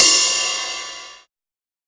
• Big Room Ride E Key 03.wav
Royality free ride tuned to the E note. Loudest frequency: 5859Hz
big-room-ride-e-key-03-EXw.wav